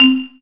error.wav